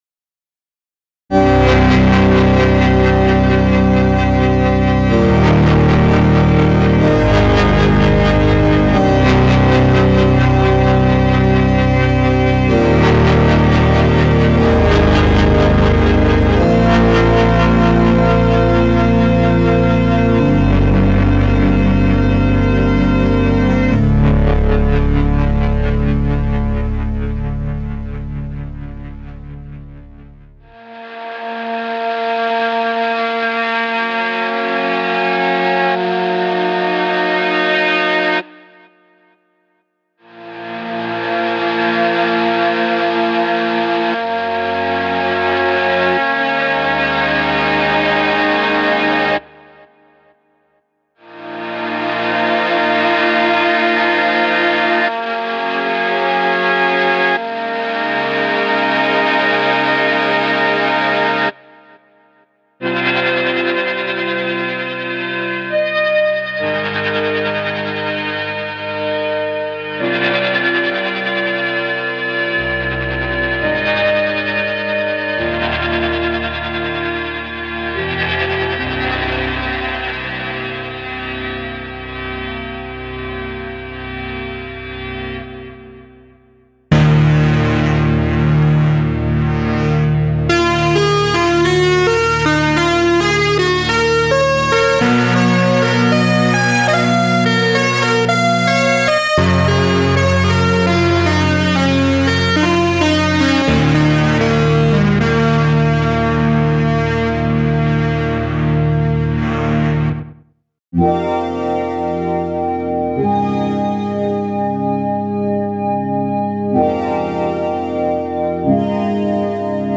A Unique Distortion-based Synthesiser for Kontakt!
• Twin detunable waveform generators: blend between pure sines and erratic, complex eBow guitar strings
In doing so, it imparts a whole new character to ‘typical’ synth tones, and drifts easily into territory that a typical subtractive synth can’t really reach, making it perfect for high-energy, aggressive synth sounds, or subtler tones with an unusual, airy edge!
Uproar Patches Demo
“Uproar” excels at aggressively powerful tones: dial everything to the max, and you are straight into snarling monster-rock territory, with sounds that have a distinctly electric-guitar edge and can cut through a mix like an acetylene flame through butter.
Uproar-Patch-Demo.mp3